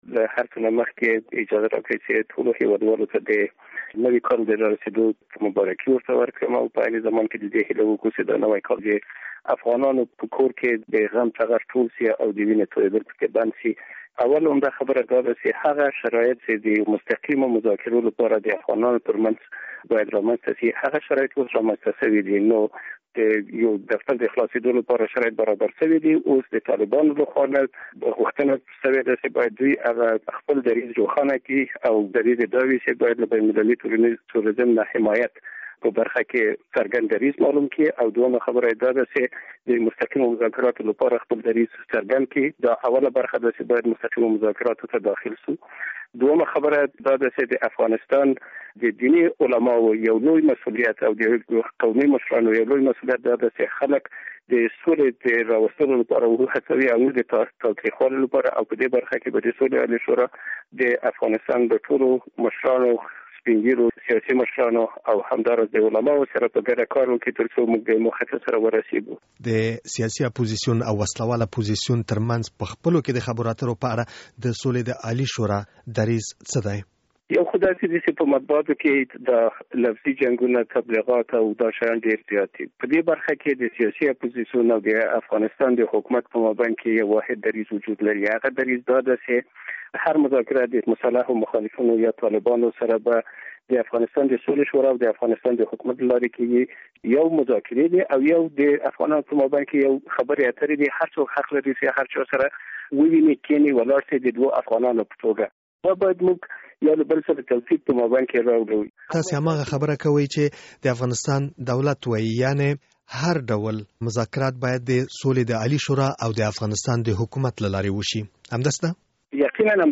له معصوم ستانکزي سره مرکه